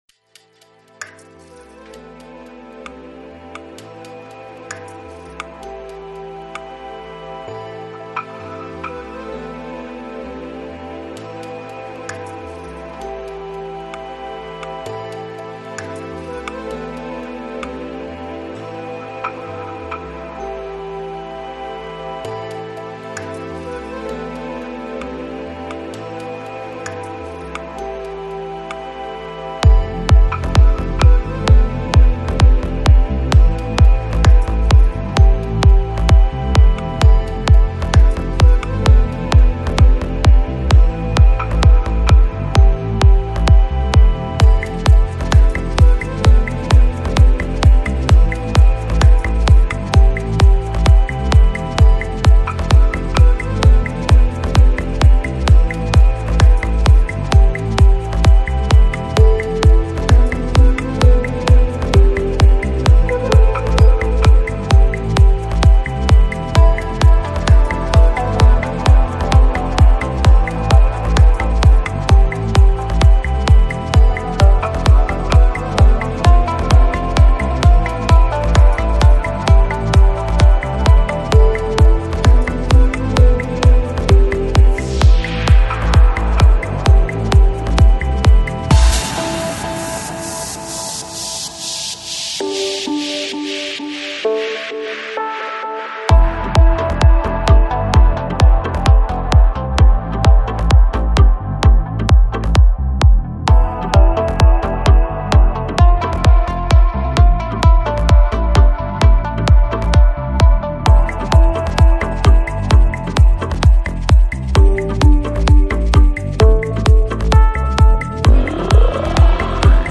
Жанр: Psychill